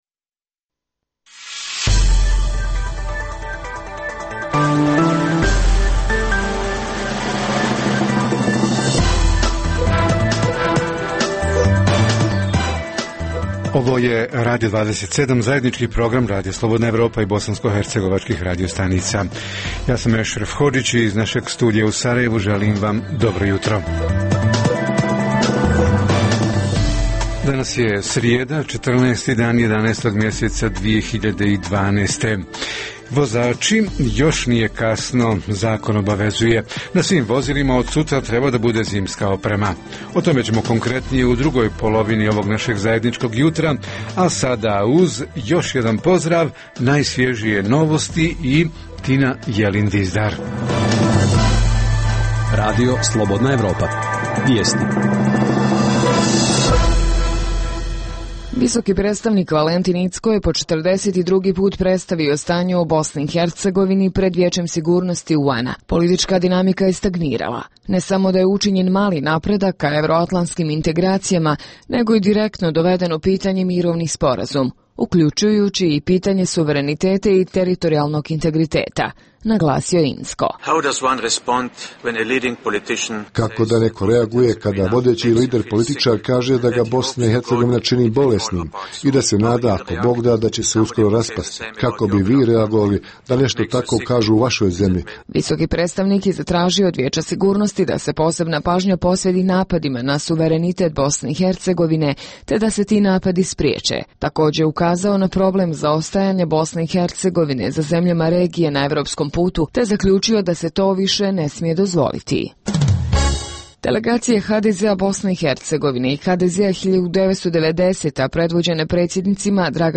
Radio 27 - Jutarnji program za BiH